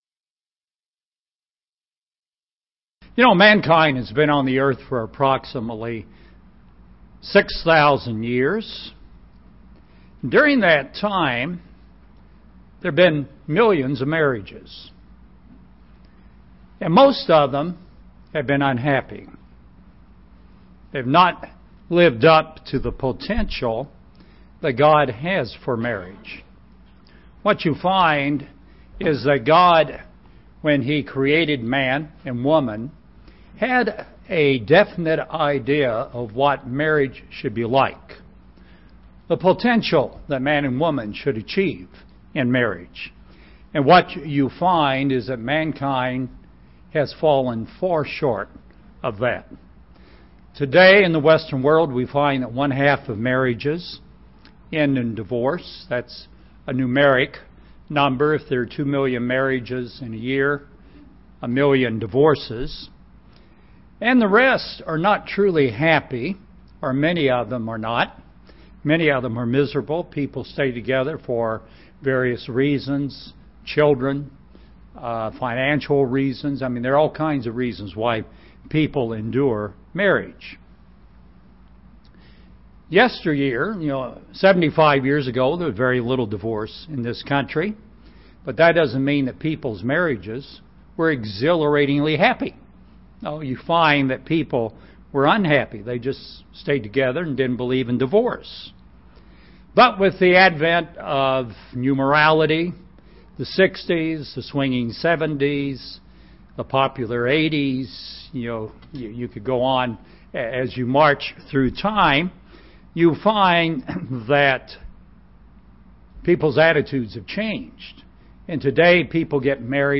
Our marriages are to be different from the world's. UCG Sermon Transcript This transcript was generated by AI and may contain errors.